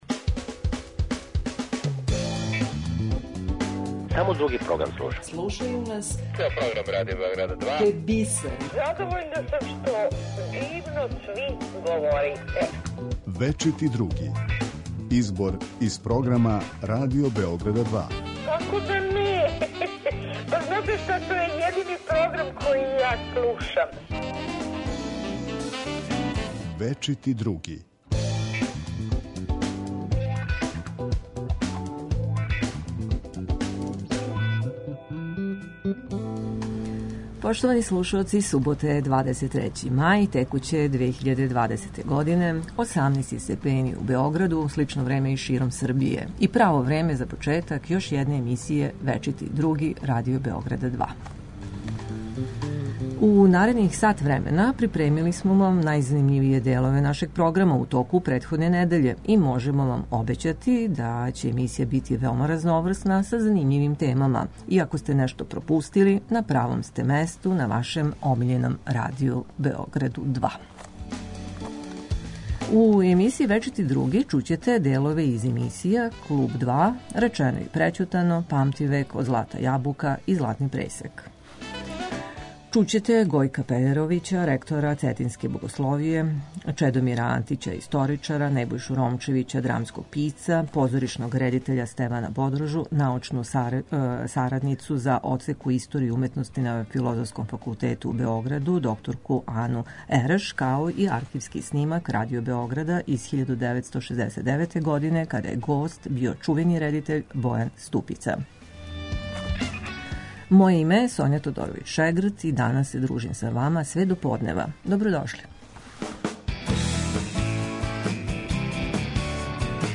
као и архивски снимак Радио Београда из 1969. године